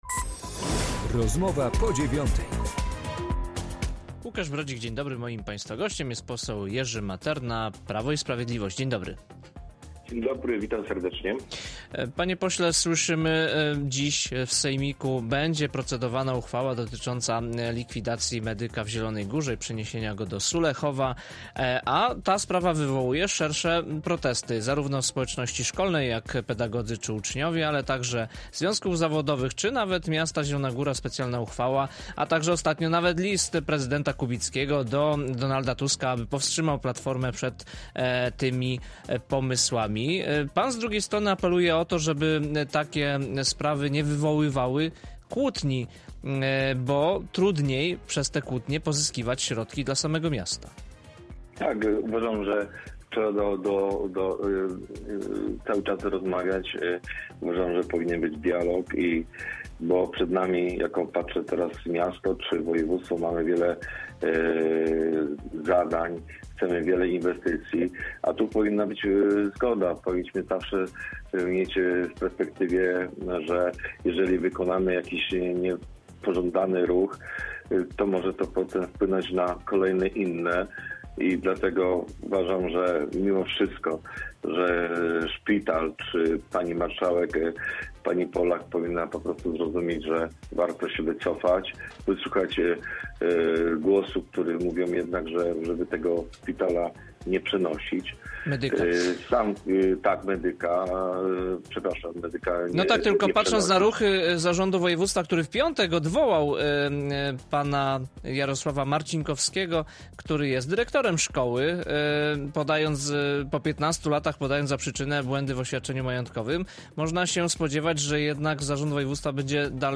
Jerzy Materna, poseł PiS